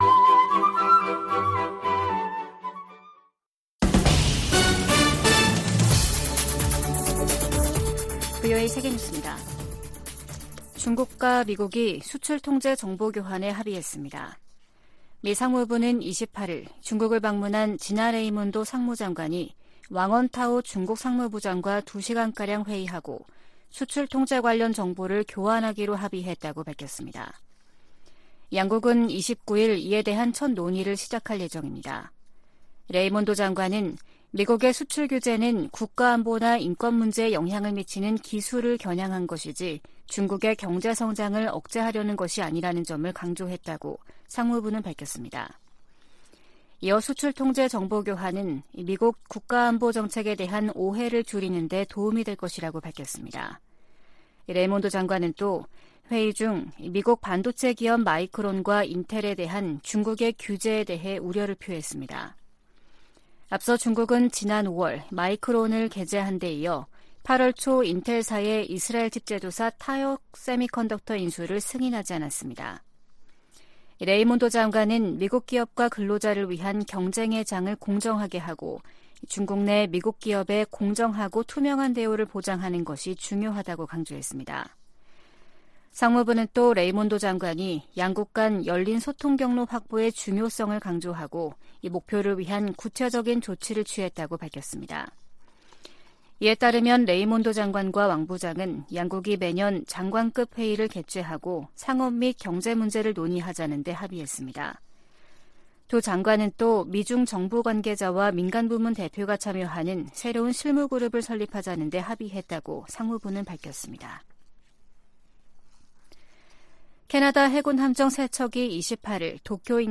VOA 한국어 아침 뉴스 프로그램 '워싱턴 뉴스 광장' 2023년 8월 29일 방송입니다. 유엔 안보리의 북한 정찰위성 발사 시도 대응 공개회의에서 미한일 등은 반복적으로 이뤄지는 도발을 규탄했습니다. 미 국무부가 후쿠시마 원전 오염처리수 방류 결정을 지지한다는 입장을 밝혔습니다. 북한이 신종 코로나바이러스 감염증 사태 이후 3년 7개월여만에 국경을 개방했습니다.